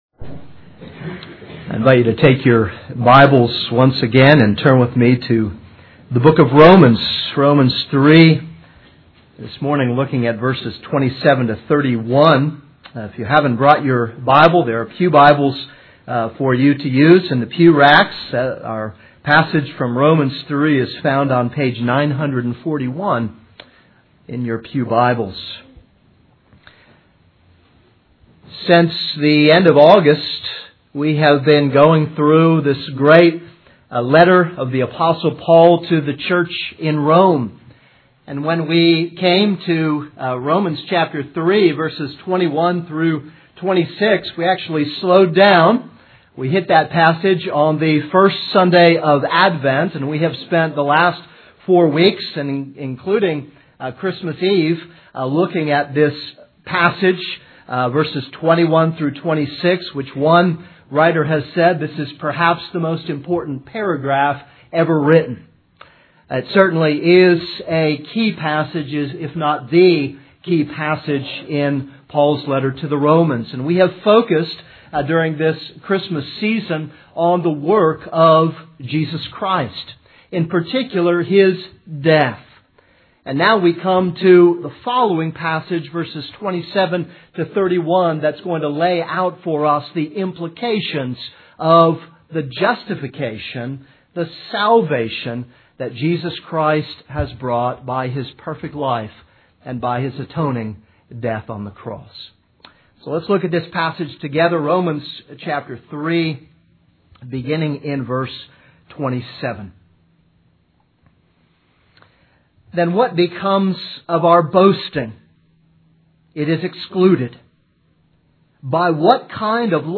This is a sermon on Romans 3:27-31.